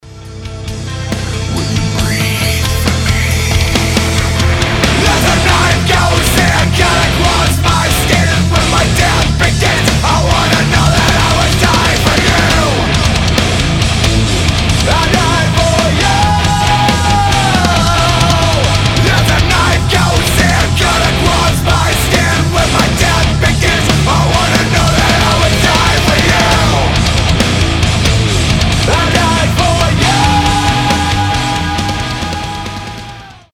• Качество: 320, Stereo
громкие
жесткие
грустные
Alternative Metal
nu metal